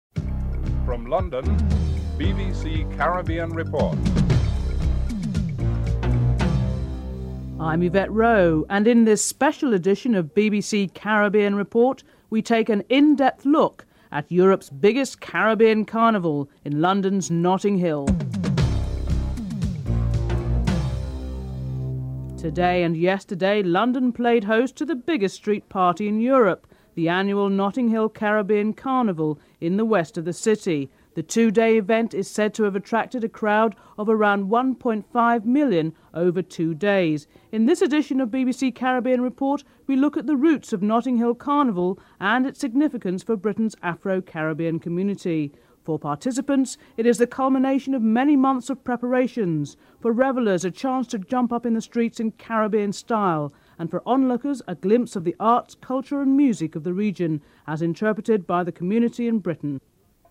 1. Headlines (00:00-00:21)